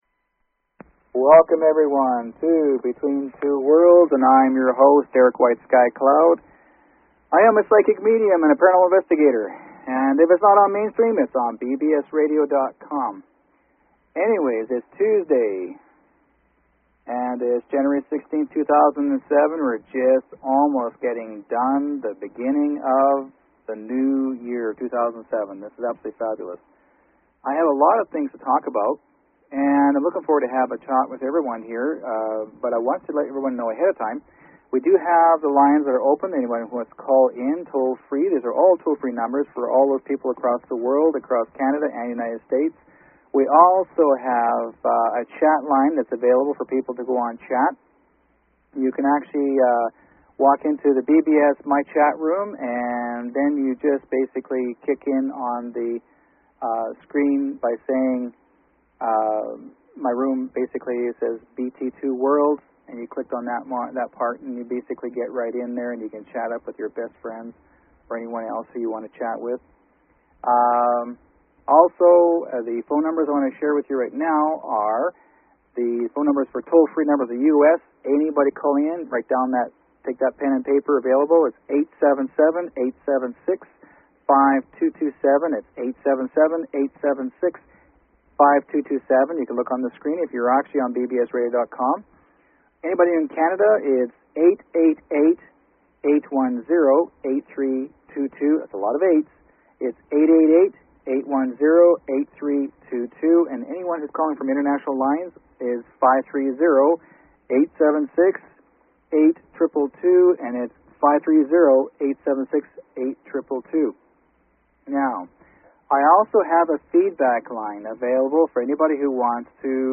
Talk Show Episode, Audio Podcast, Between_Two_Worlds and Courtesy of BBS Radio on , show guests , about , categorized as